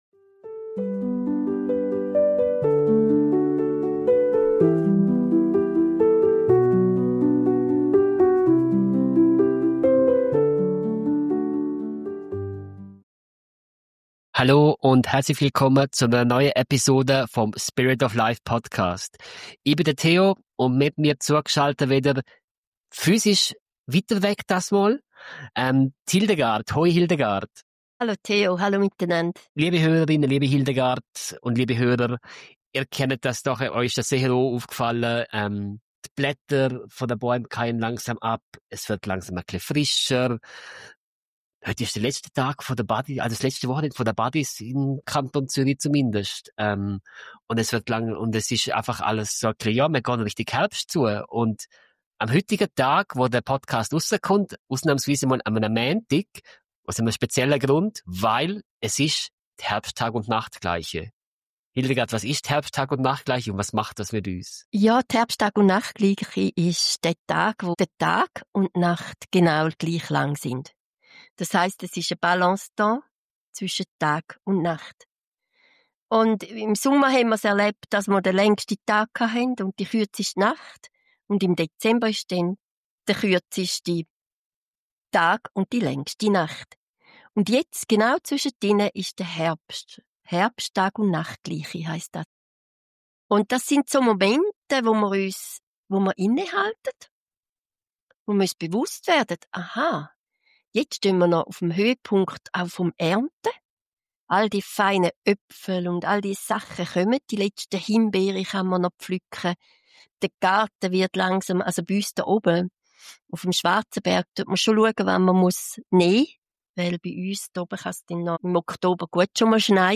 Es geht um Rituale mit Feuer, Blumen und Räucherwerk, um Reflexion über das, was uns stärkt oder nicht mehr dient, und um die Frage: Was ist meine innere Ausrichtung, mein Kompass? Ein Gespräch über Dankbarkeit, Balance und den Mut, Angst in Liebe zu verwandeln.